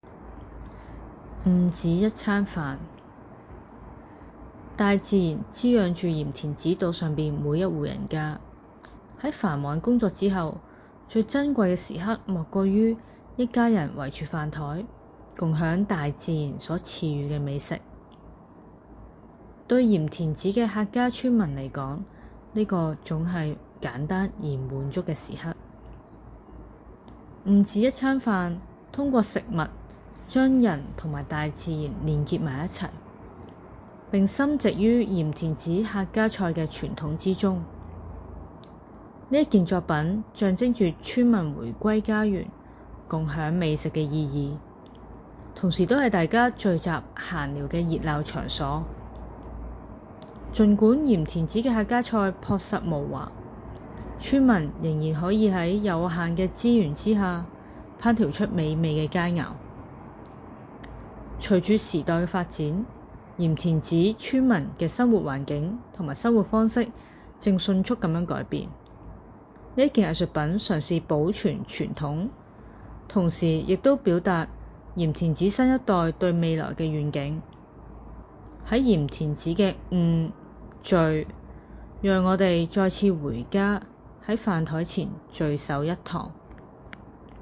《晤梓一餐飯》— 藝術家原聲介紹按此閱讀原聲介紹文字稿